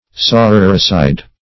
Search Result for " sororicide" : The Collaborative International Dictionary of English v.0.48: Sororicide \So*ror"i*cide\ (?; 277), n. [L. sororocida, and sororicidium; soror a sister + caedere to kill.] The murder of one's sister; also, one who murders or kills one's own sister.